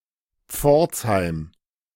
Pforzheim (German pronunciation: [ˈpfɔʁtshaɪm]